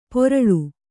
♪ poraḷu